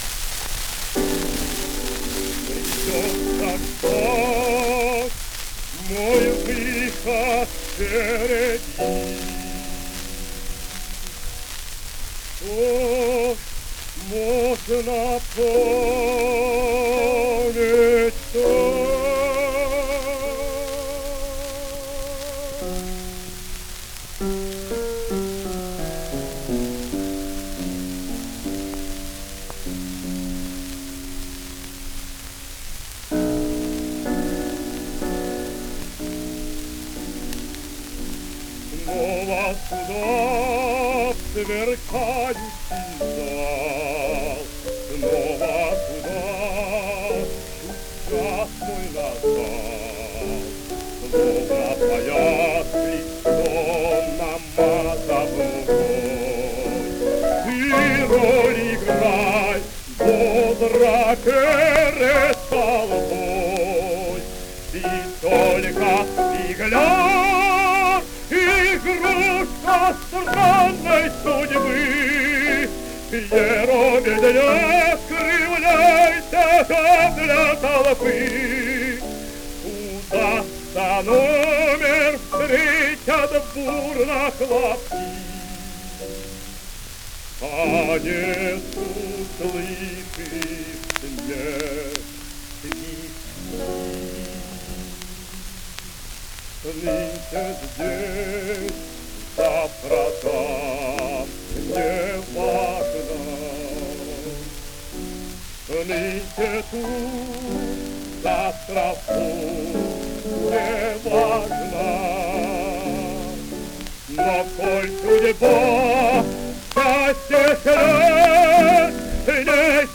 Певческий голосбаритон
Жанрыопера, оперетта
М. И. Днепров, арии и дуэты